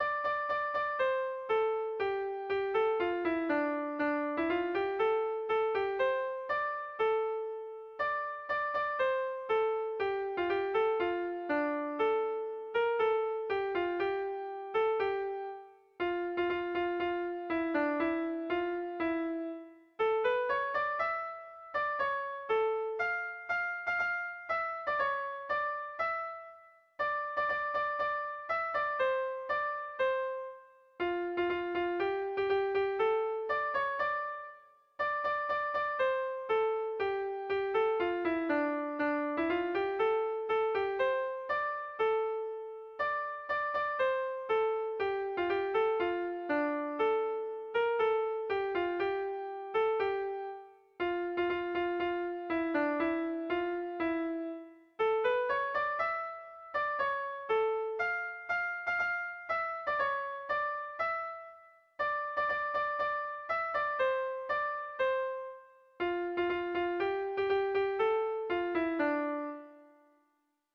Irrizkoa